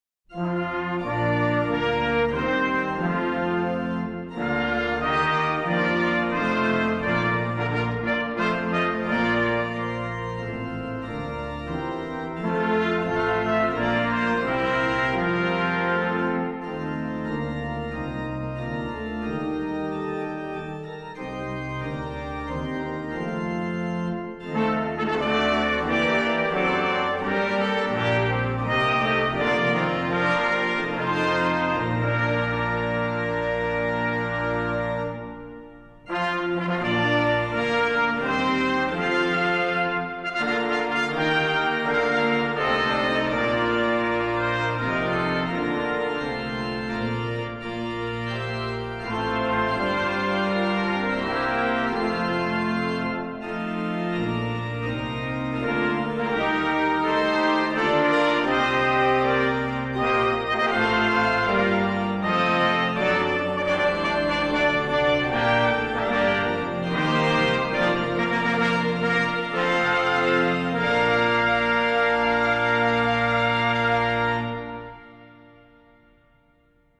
Voicing: SATB, Congregation, Optional Soprano Descant